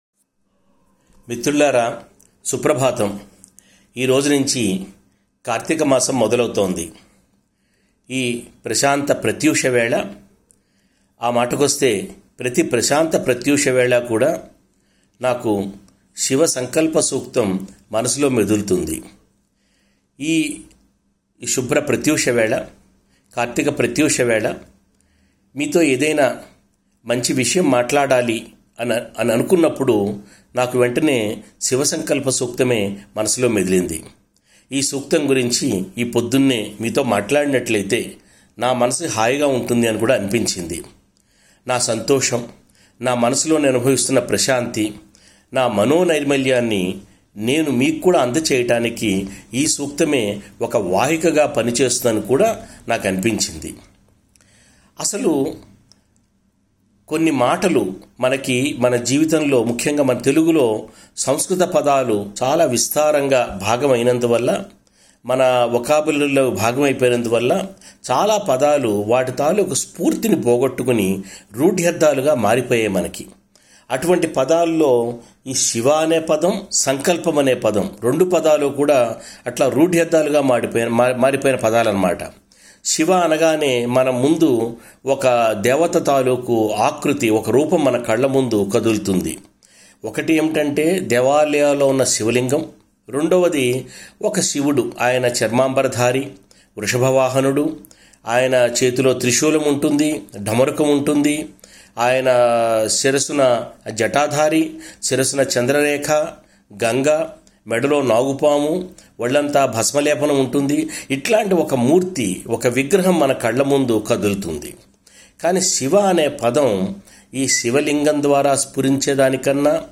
కార్తికమాసం మొదలవుతున్న ఈ ప్రత్యూషవేళ నా తలపులూ, మీ తలపులూ కూడా శివ సంకల్పమయం కావాలనే శుభాకాంక్షతో 'శివ సంకల్ప సూక్తం' పైన నా ప్రసంగాన్ని మీతో పంచుకుంటున్నాను. నలభైనిమిషాల ప్రసంగం.